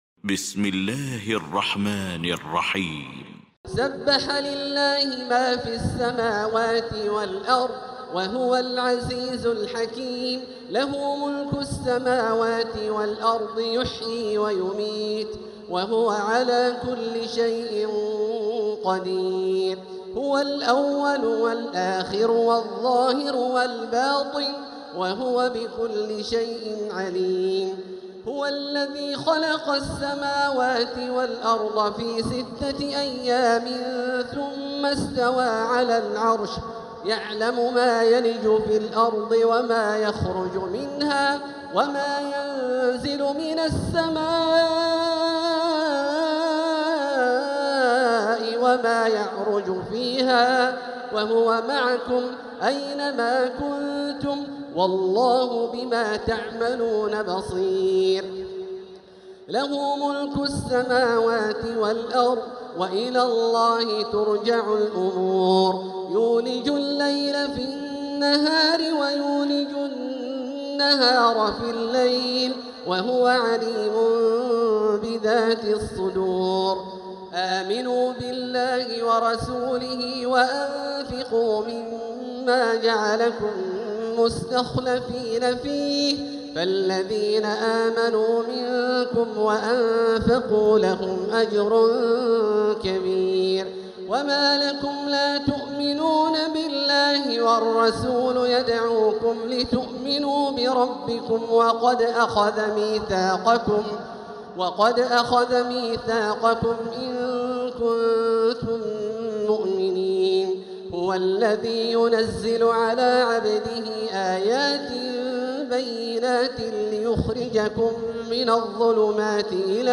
المكان: المسجد الحرام الشيخ: فضيلة الشيخ عبدالله الجهني فضيلة الشيخ عبدالله الجهني الحديد